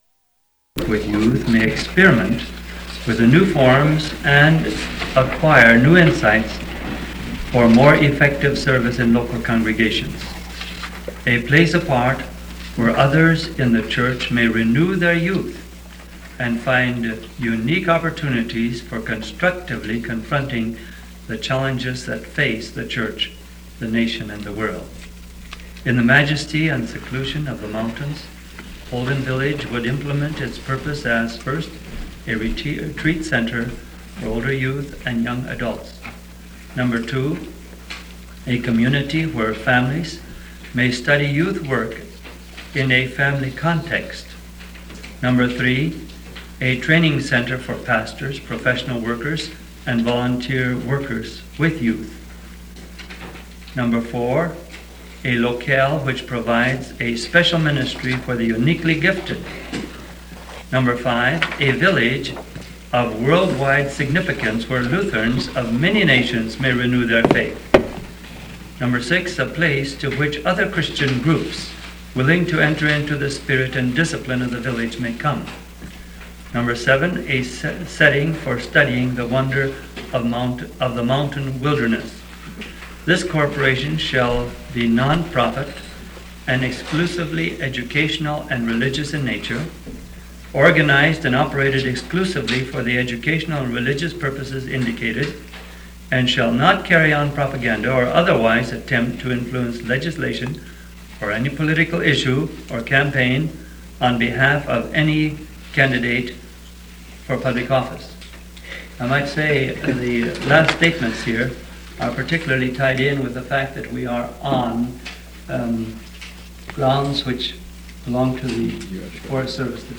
Holden Village Board Meeting 1965: Part 3 - Discussion of Purpose of Holden Corporation Drawn up in 1963 | Holden Village Audio Archive